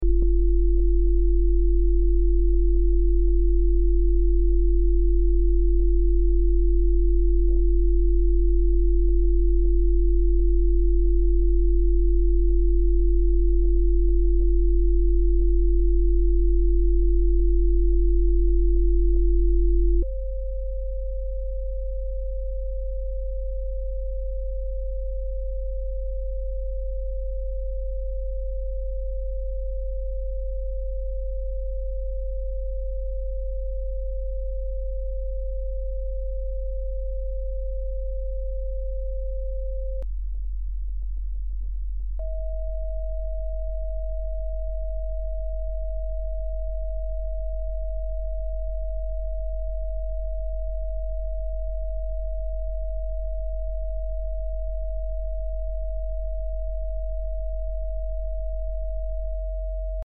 Ritual de 1 minuto para el vitiligo: Esta es la frecuencia de 1 minuto del audio real de 30 minutos. Te guiará a través de un proceso de 3 pasos para armonizar tus células. Armonía celular (0-20 segundos): Siente la calma interna y la paz de tu sistema inmune con el mantra Su.